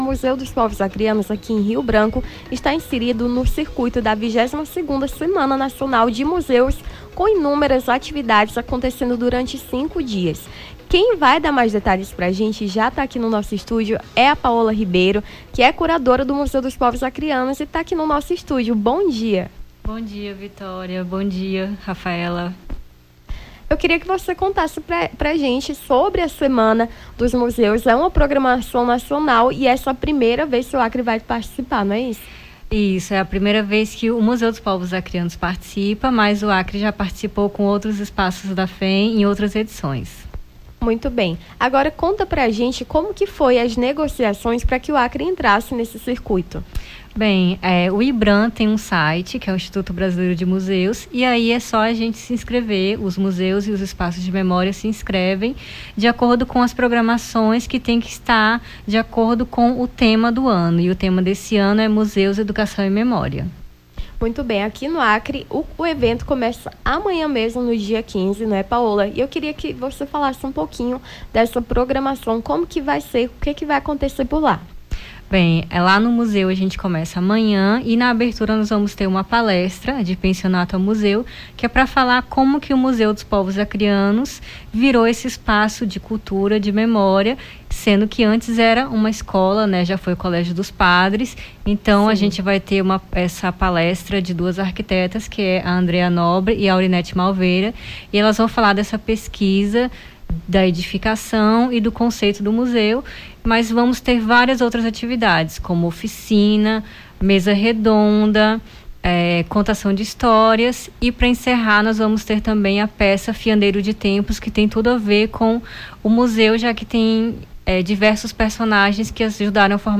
Nome do Artista - CENSURA - ENTREVISTA SEMANA DO MUSEU (14-05-24).mp3